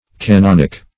canonic \ca*non"ic\ (k[.a]*n[o^]n"[i^]k), canonical